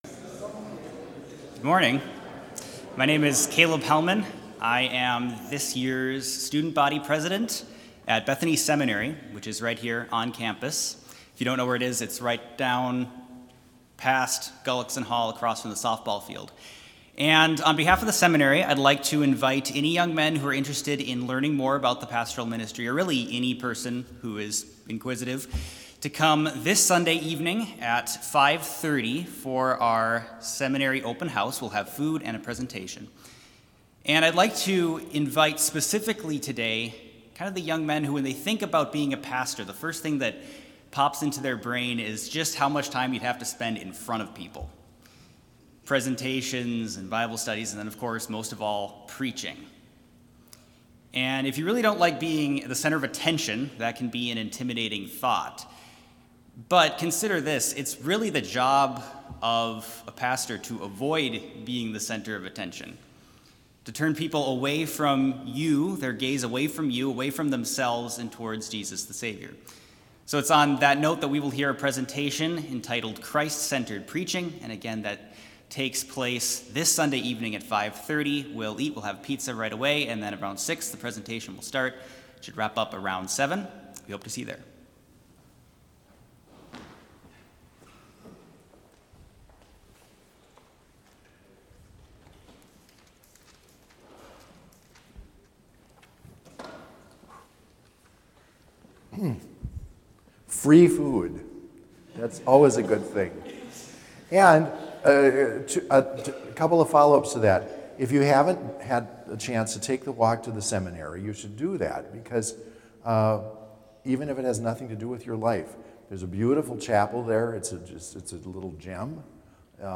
Chapel worship service held on November 11, 2021, BLC Trinity Chapel, Mankato, Minnesota
Complete service audio for Chapel - November 11, 2021